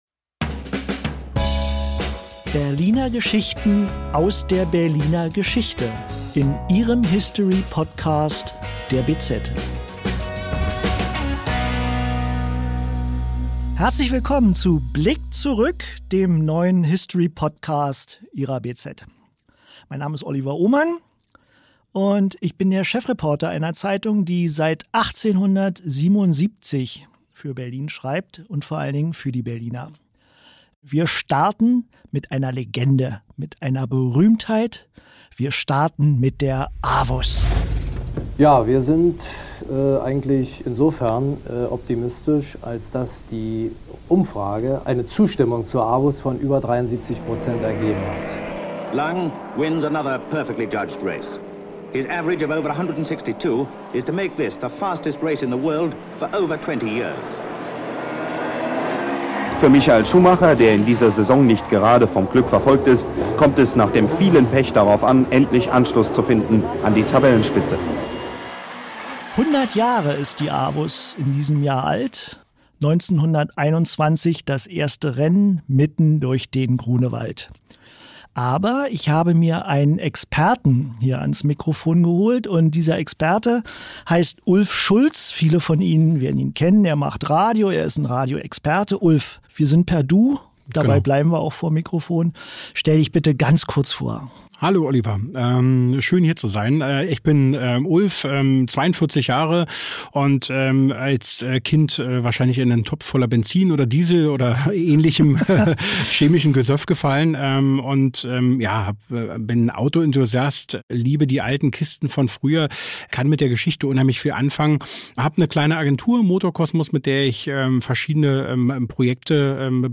Die Avus wurde vor 100 Jahren eröffnet. Experten und Zeitzeugen erzählen Geschichten aus der Geschichte der legendären Berliner Rennstrecke durch den Grunewald.